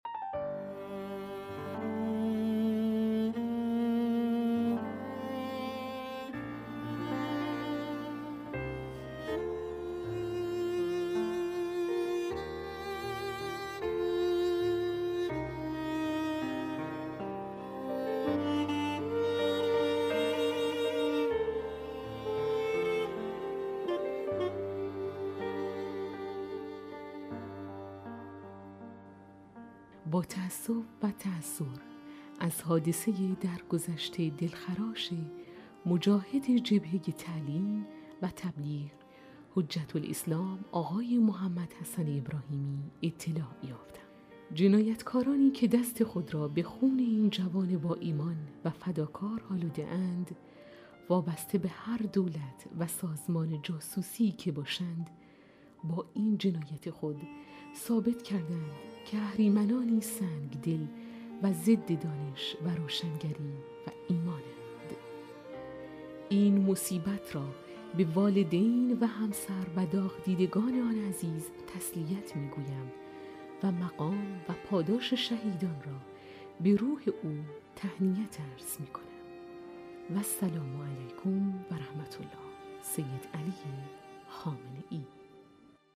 کتاب صوتی/ «ماه گویان» قسمت اول
کتاب صوتی